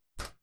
Weapon Melee Attack Impact.wav